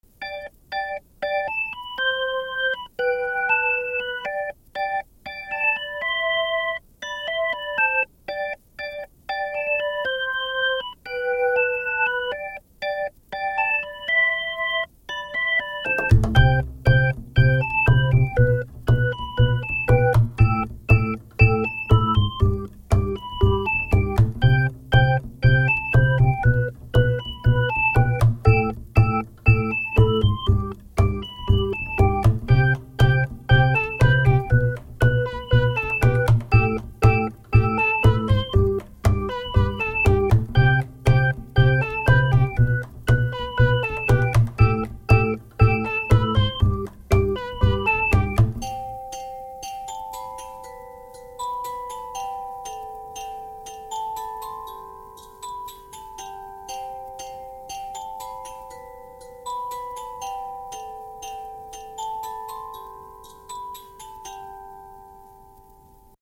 So I've made this cute background music.
cute_0.mp3